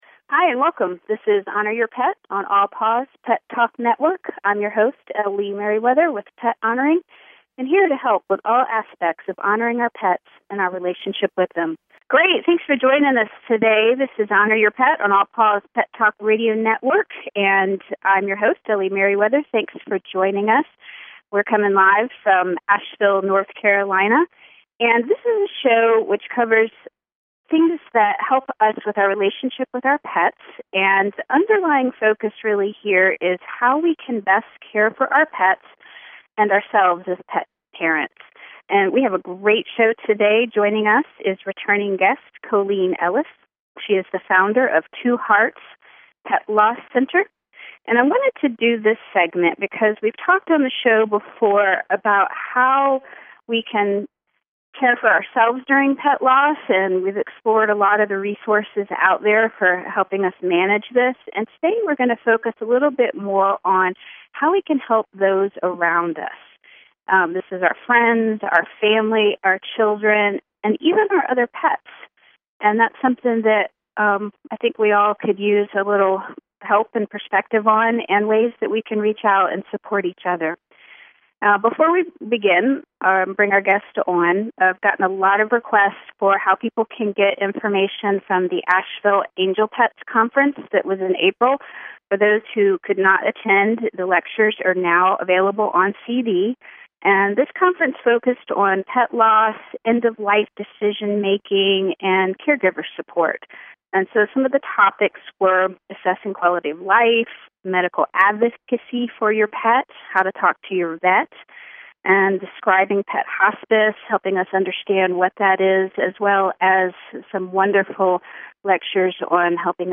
Honor Your Pet is a talk radio show covering all things which honor our pets and our relationship with them. These include innovative and holistic pet care topics as well as addressing the difficult, but honorable end-of-life times and healing grief from pet loss.